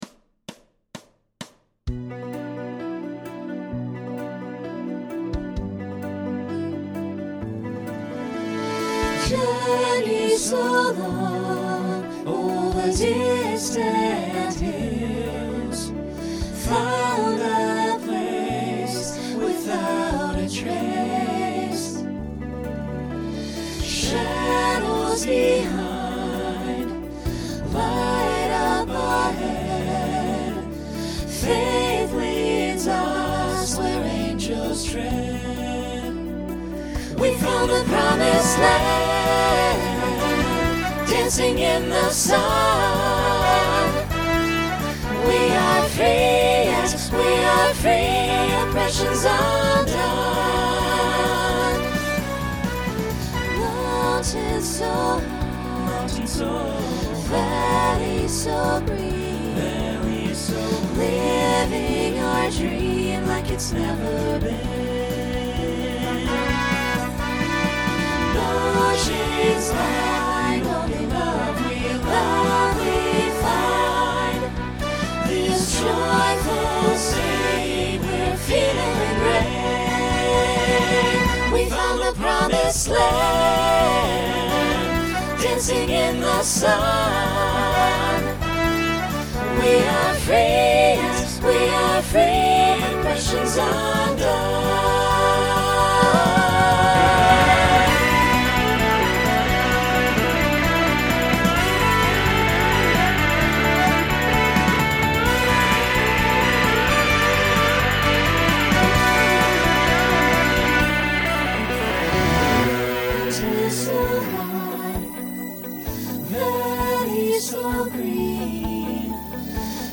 Genre Rock
Original Song Show Function Closer Voicing SATB